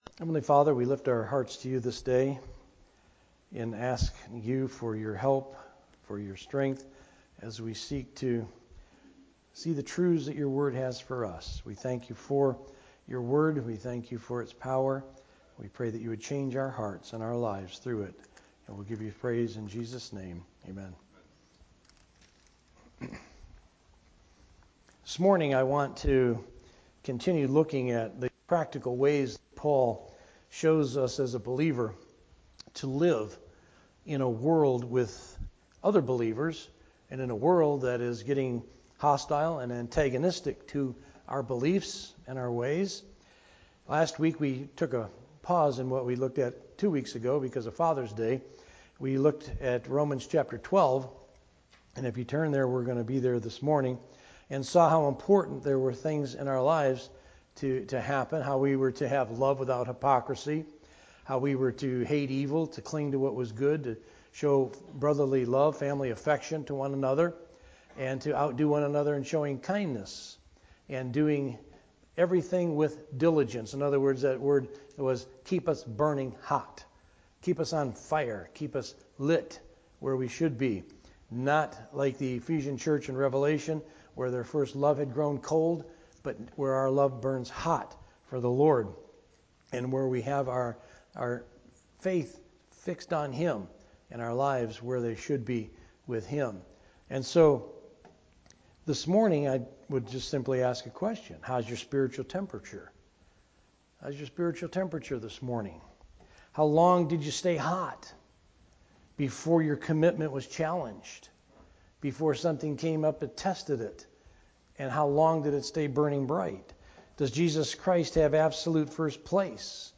Sermon Audio | FCCNB